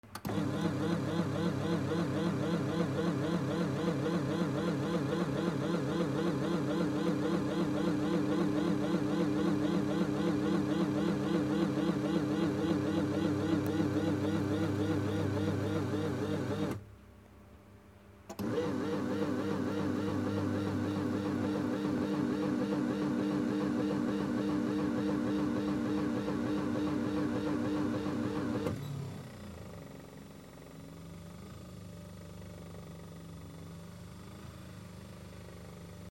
マッサージ機 モーター音